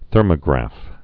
(thûrmə-grăf)